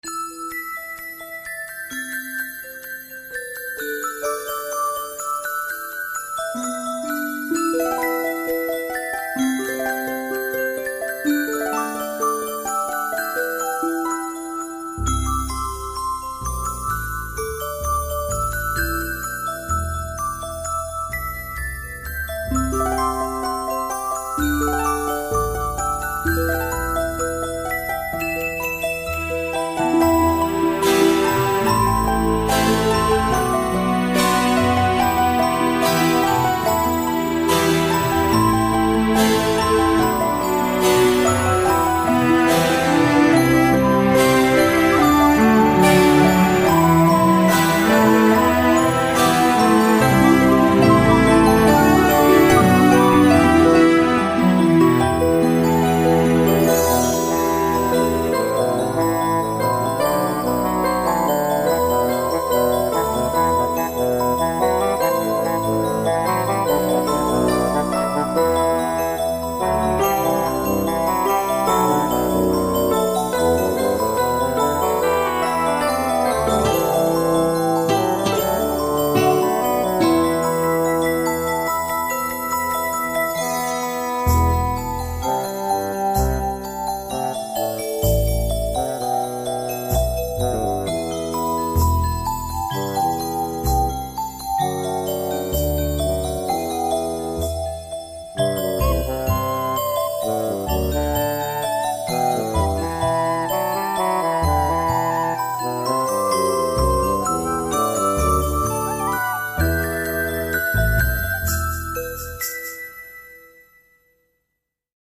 All instruments performed by me.